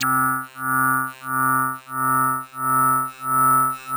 Rezzy Lead C3.wav